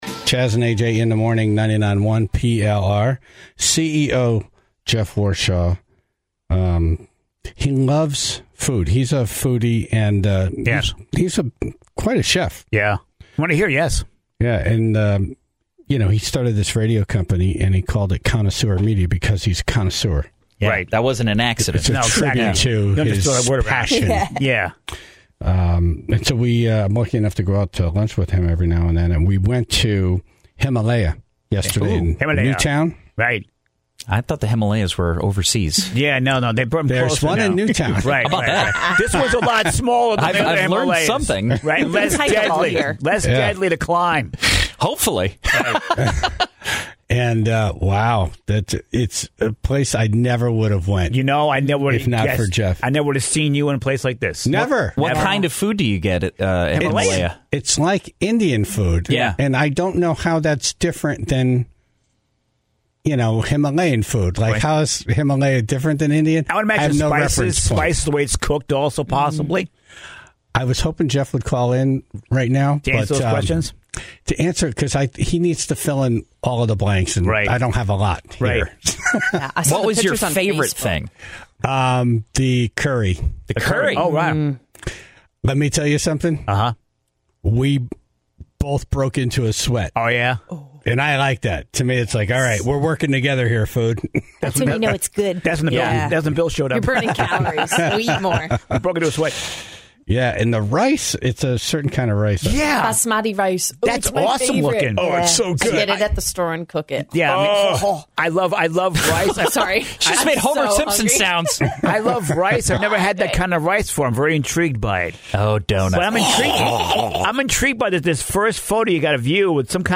(0:00) In Dumb Ass News, an airline pilot was fired for doing drugs, but the highlight here was the computer robot AI voice dictating the news article.